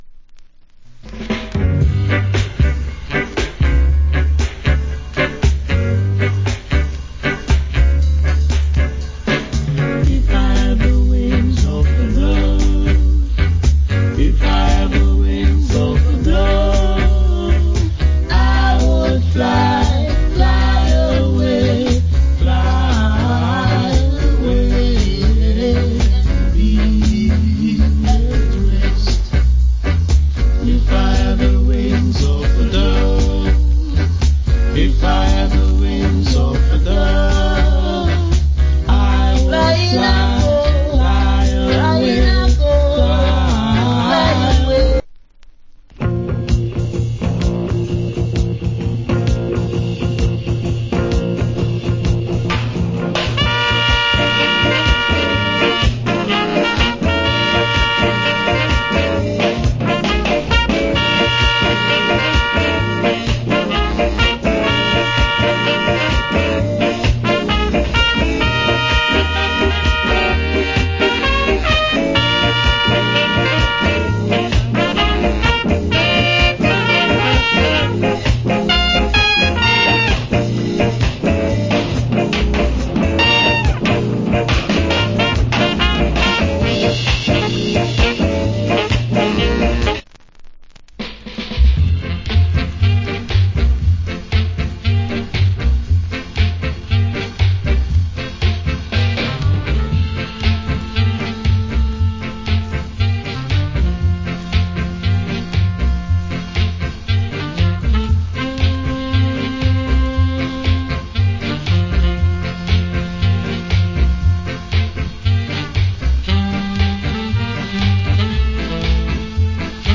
Nice Authentic Ska.